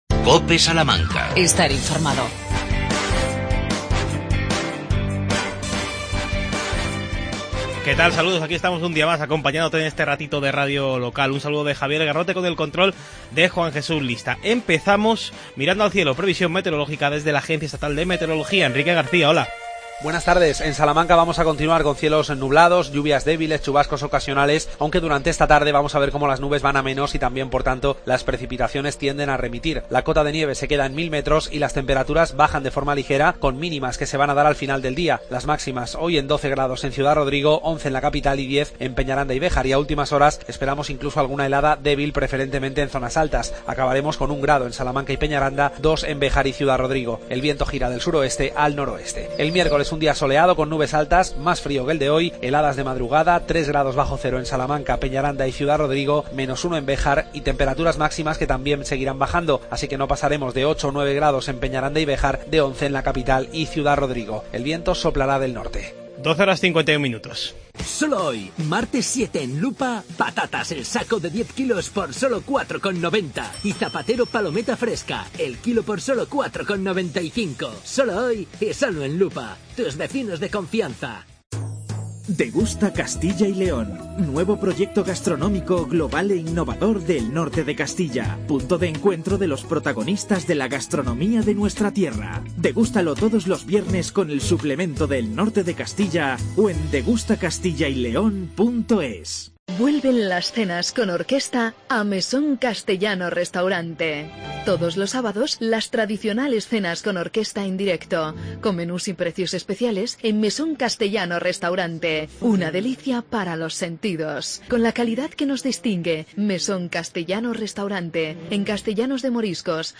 Redacción digital Madrid - Publicado el 07 feb 2017, 13:03 - Actualizado 18 mar 2023, 22:06 1 min lectura Descargar Facebook Twitter Whatsapp Telegram Enviar por email Copiar enlace La concejal Cristina Klimowitz nos habla del convenio entre el Ayuntamiento y Gas Natural Fenosa.